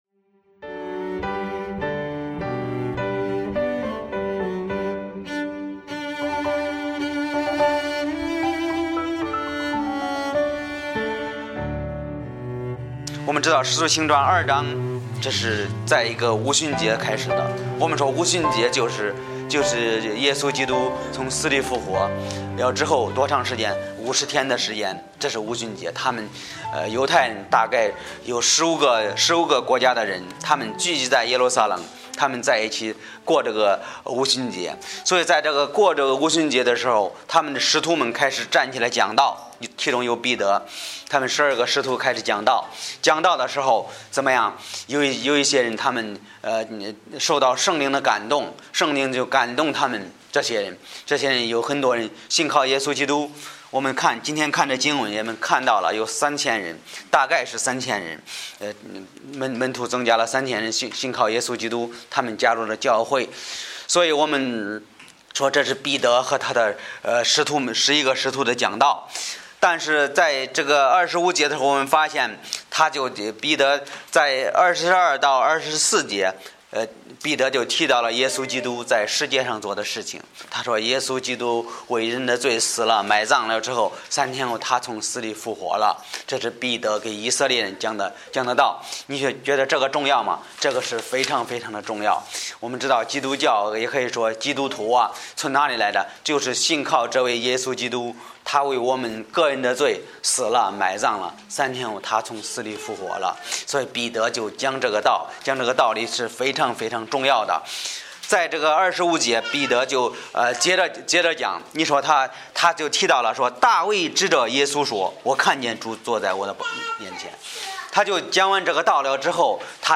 Bible Text: 使徒行传2:25-40 | 讲道者